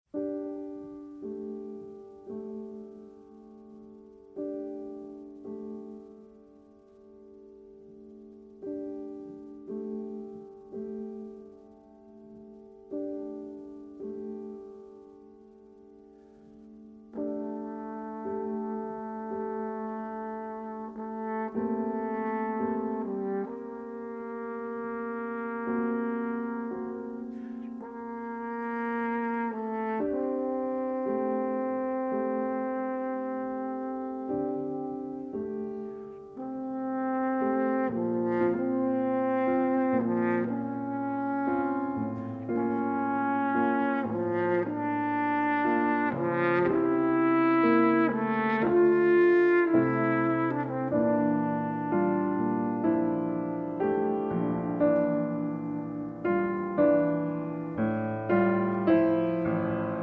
horn
piano